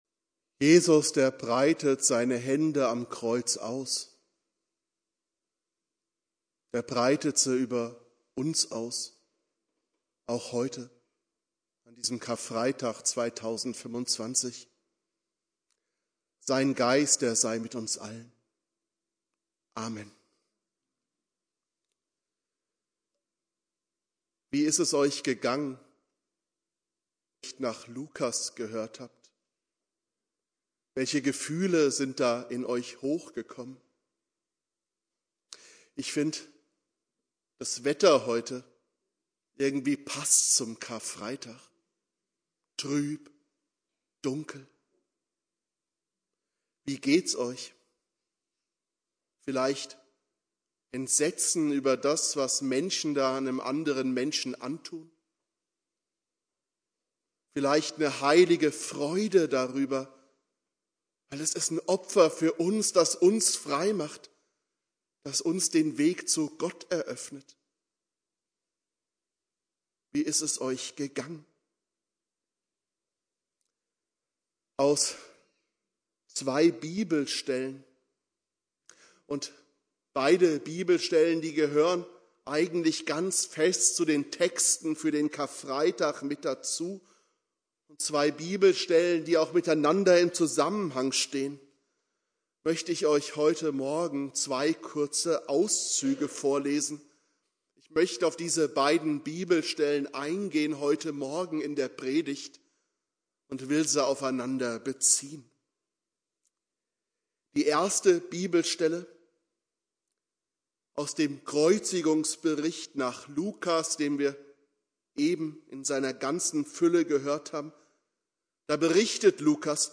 Predigt
Karfreitag Prediger